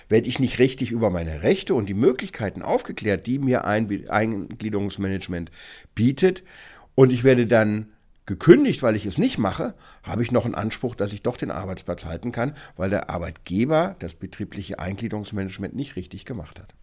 O-Ton: Betriebliches Eingliederungsmanagement